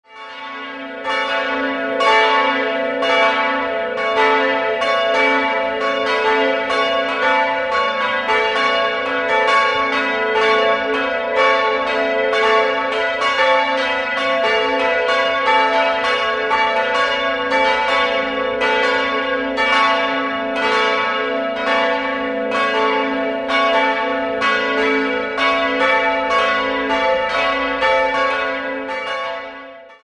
3-stimmiges Paternoster-Geläute: a'-h'-cis''